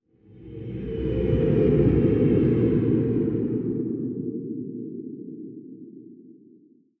Minecraft Version Minecraft Version latest Latest Release | Latest Snapshot latest / assets / minecraft / sounds / ambient / nether / warped_forest / here2.ogg Compare With Compare With Latest Release | Latest Snapshot